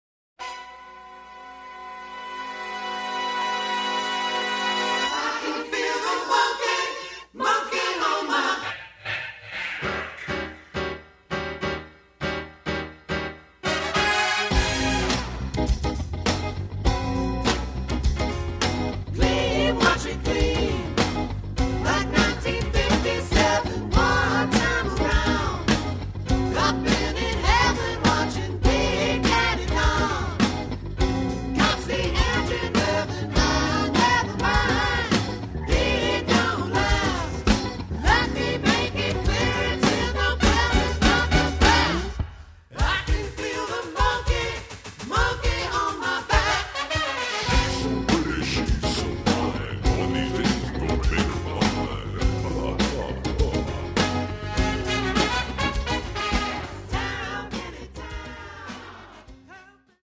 and I worked on the arrangement of the horn parts.
Keyboards
Gear: Yamaha TG55, Oberheim Xk (controller)